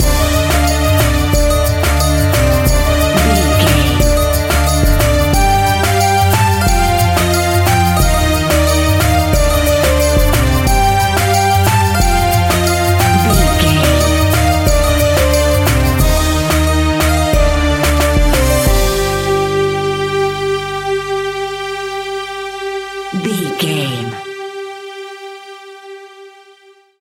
Aeolian/Minor
ethnic
World Music
percussion